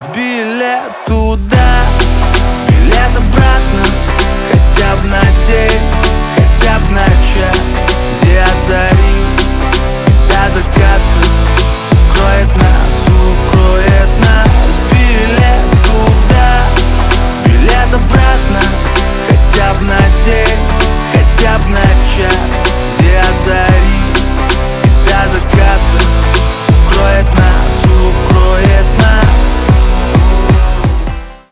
поп
битовые
гитара